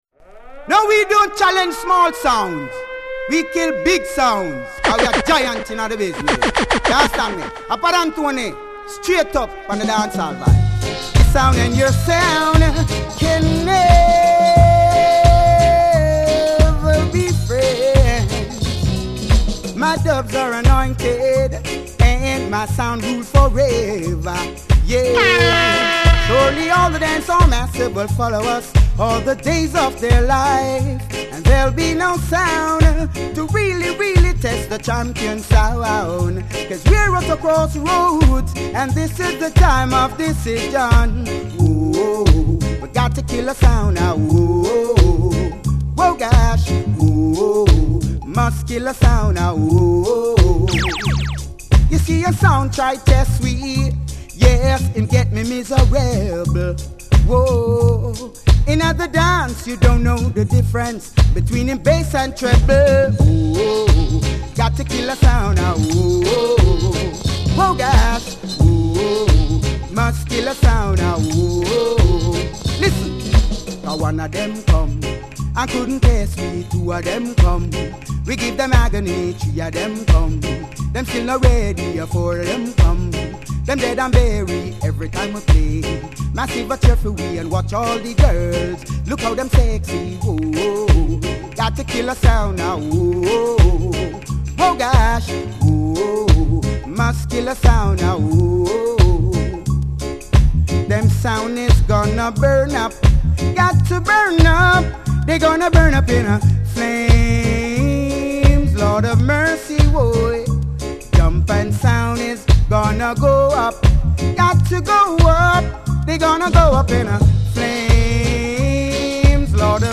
Styl: Reggae